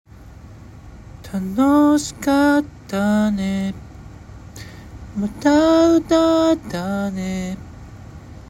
これはシンプルに歌っていて、何もせずにシンプルに声をブツっと切る歌い方で誰でもすぐにできる歌い方です。
例えば「あーーーーー」と伸ばして息を止めて声も止めるだけです。